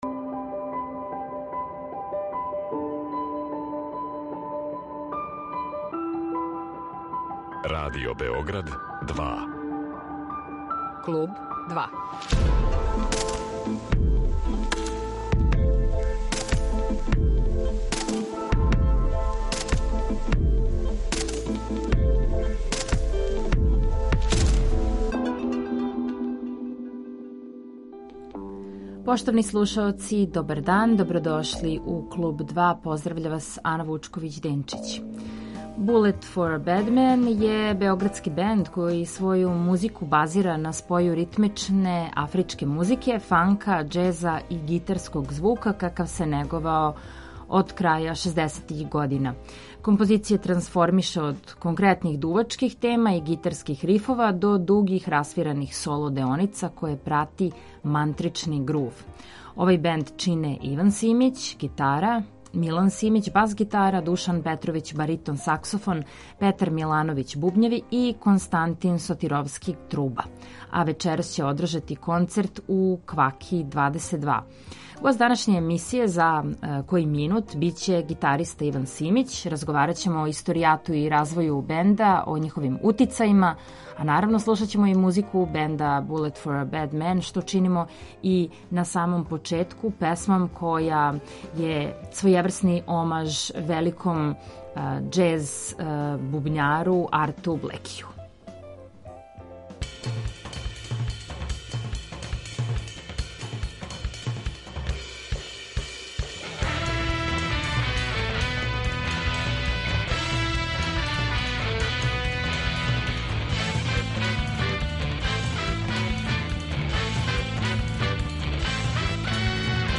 Bullet For a Badman је београдски бенд који своју музику базира на споју ритмичне афричке музике, фанка, џеза и гитарског звука какав се неговао од краја 60-их година.
Композиције трансформише од конкретних дувачких тема и гитарских рифова до дугих расвираних соло деоница које прати мантрични грув.